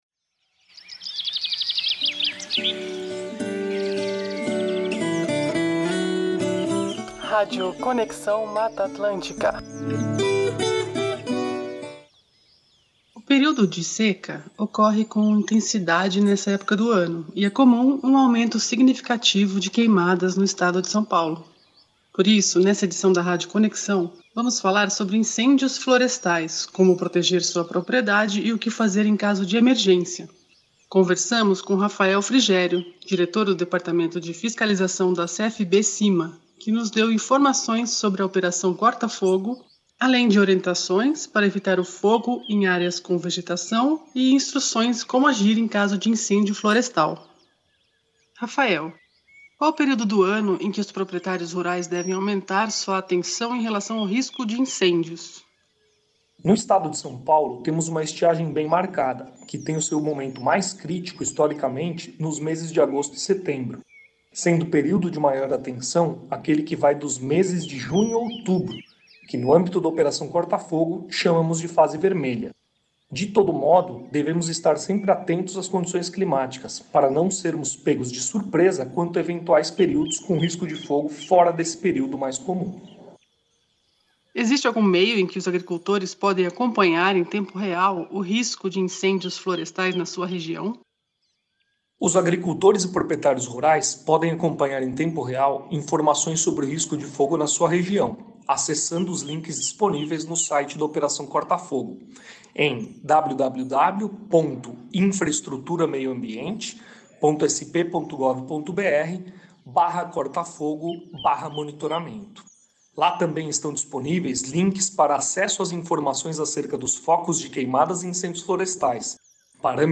Rádio Conexão Mata Atlântica apresenta: entrevista